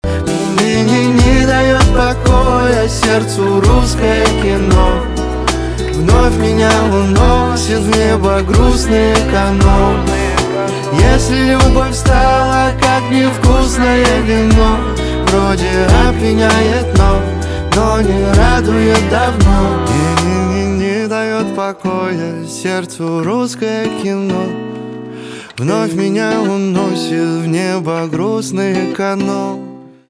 поп
гитара , барабаны , чувственные
грустные